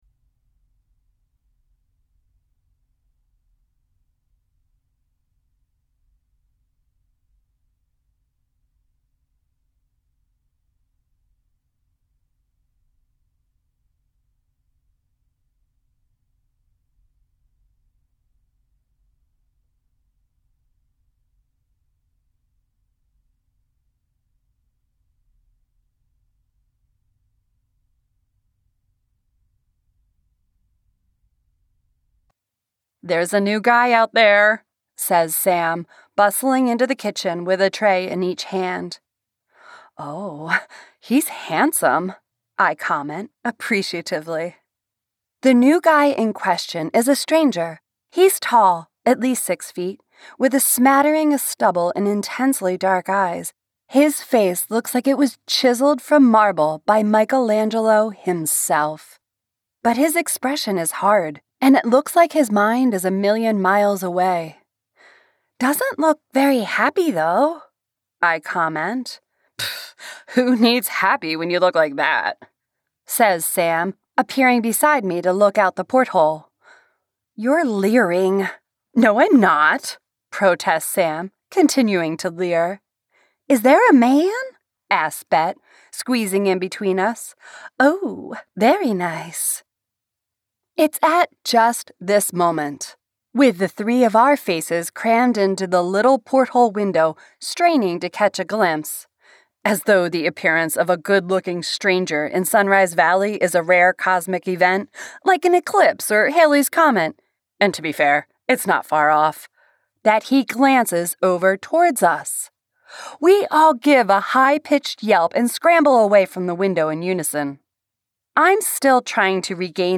Narrator
Accent Capabilities: All American accents. Also British, Russian, and Israeli.